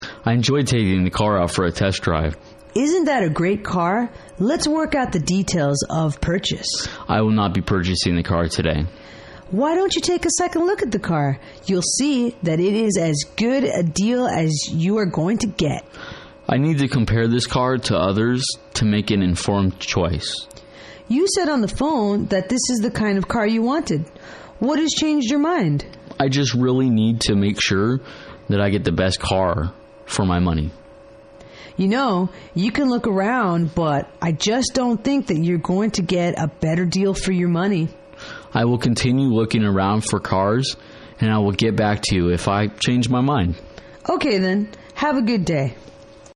英语情景对话-Declining to Buy the Car(3) 听力文件下载—在线英语听力室